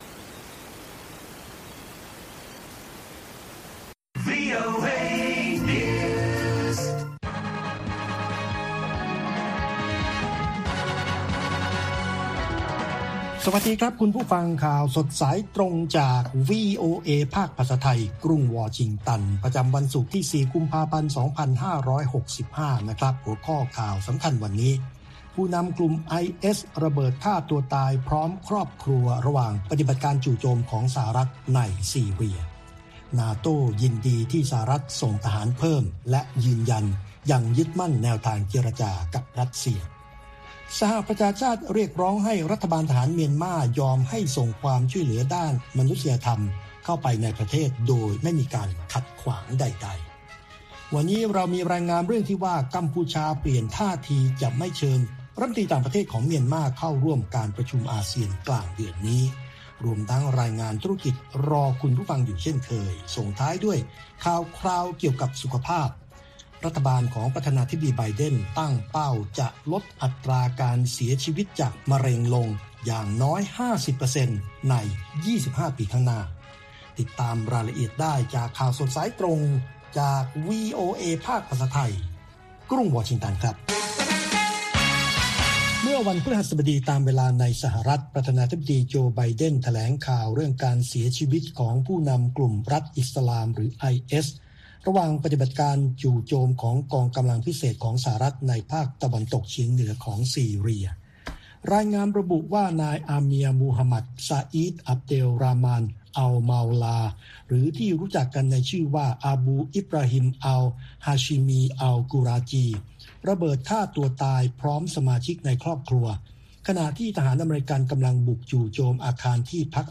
ข่าวสดสายตรงจากวีโอเอ ภาคภาษาไทย ประจำวันศุกร์ที่ 4 กุมภาพันธ์ 2565 ตามเวลาประเทศไทย